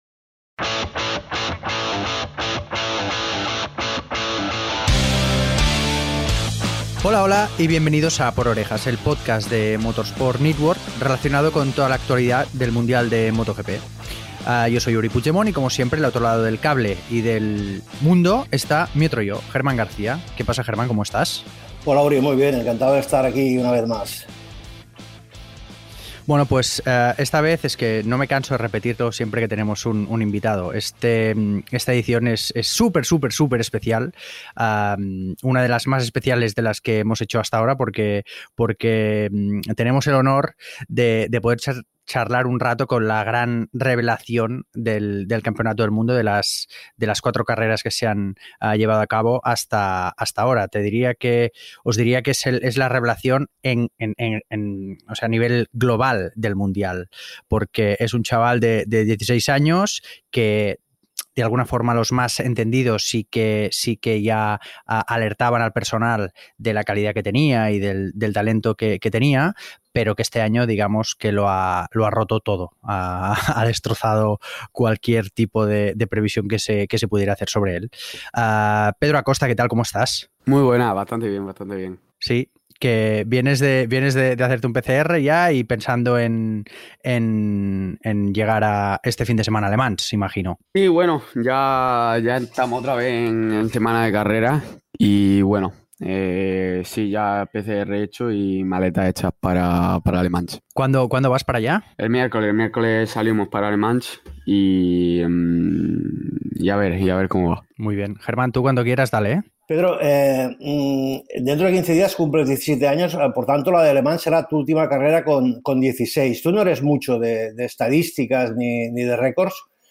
Podcast MotoGP